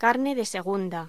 Locución: Carne de segunda
voz